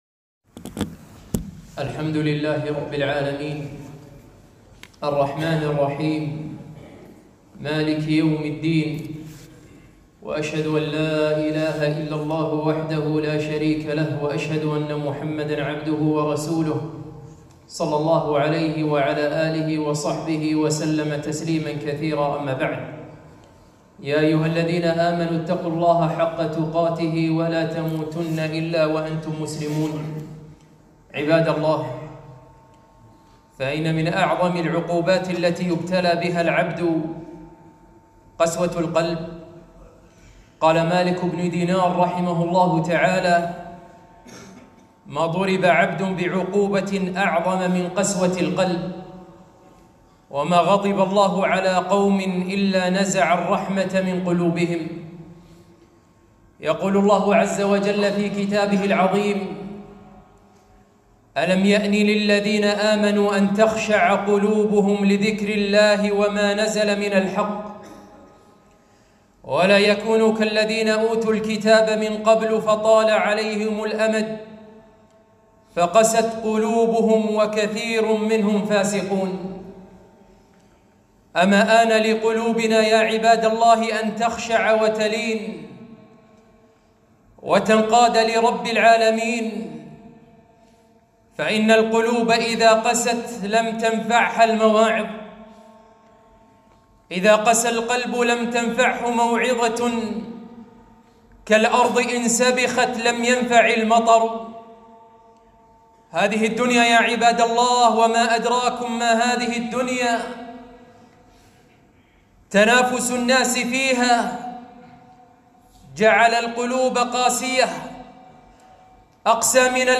خطبة - قسوة القلب